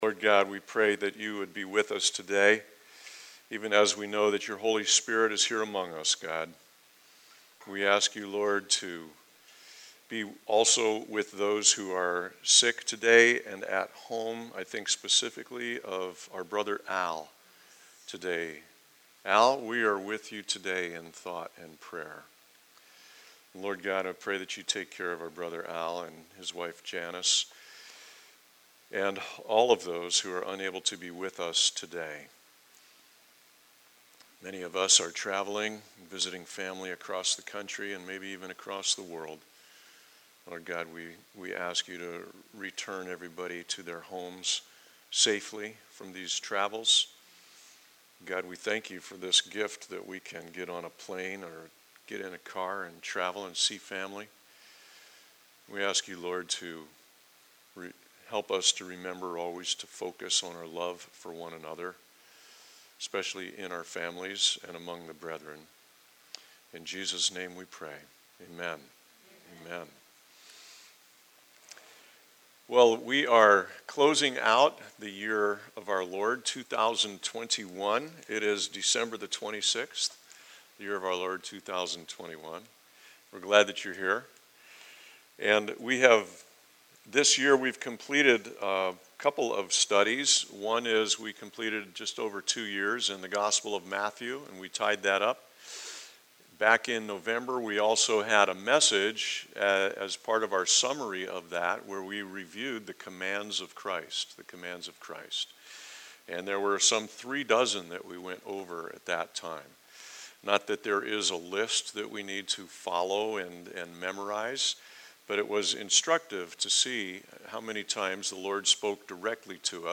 by Calvary Chapel Leesburg | Dec 26, 2021 | Sermons | 0 comments